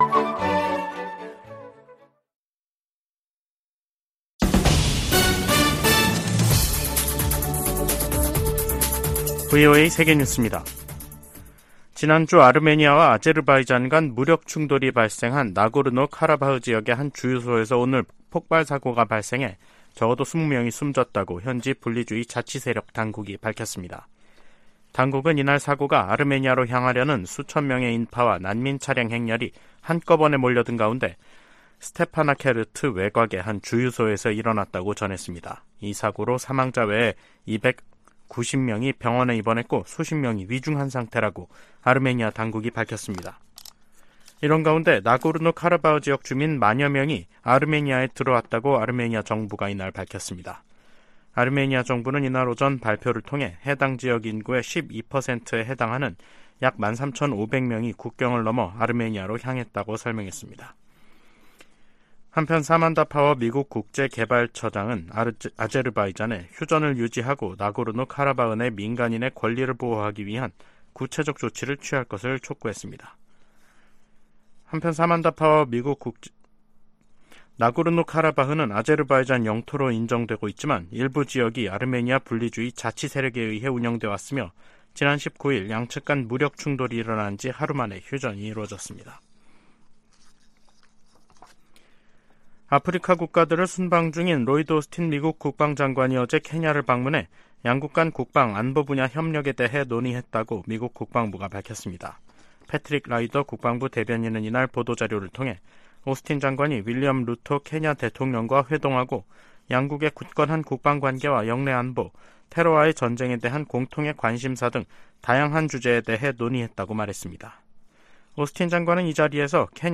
VOA 한국어 간판 뉴스 프로그램 '뉴스 투데이', 2023년 9월 26일 2부 방송입니다. 토니 블링컨 미 국무장관은 미한 동맹이 안보 동맹에서 필수 글로벌 파트너십으로 성장했다고 평가했습니다. 한국과 중국, 일본의 외교당국은 3국 정상회의를 빠른 시기에 개최하기로 의견을 모았습니다.